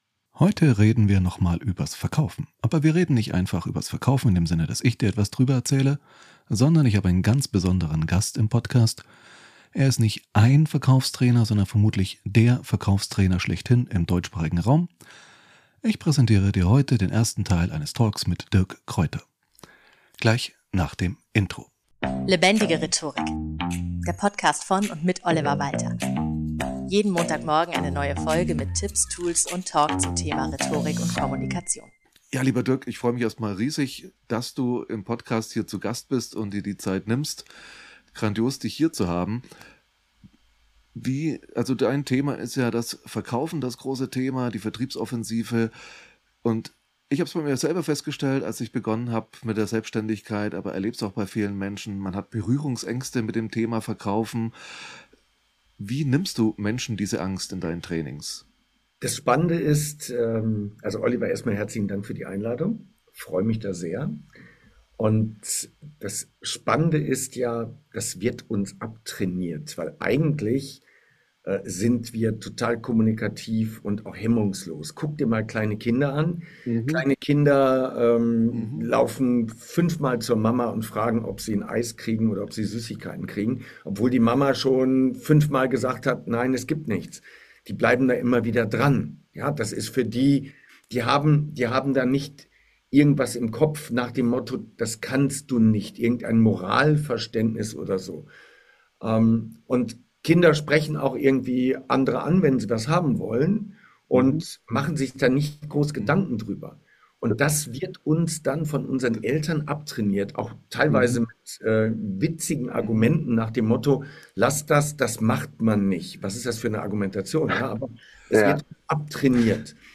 So geht Verkaufen - Expertentalk